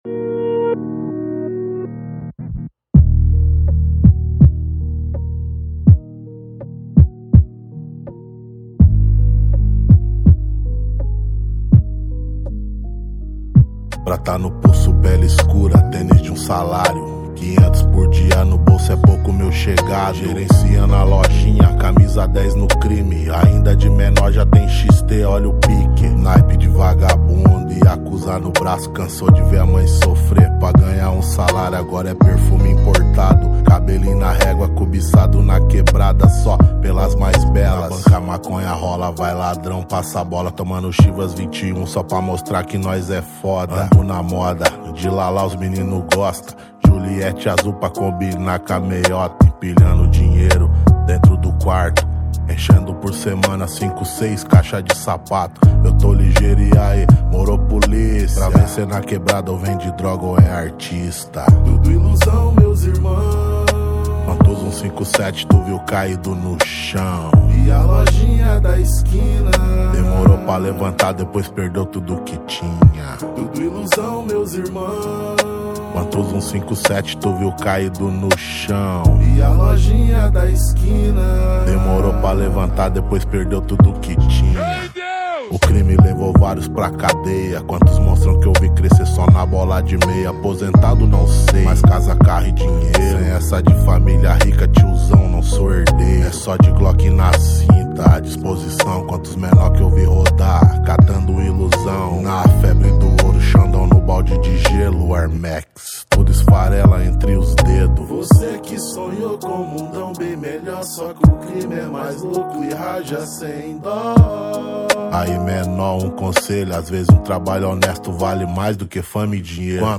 2025-03-06 21:17:44 Gênero: Rap Views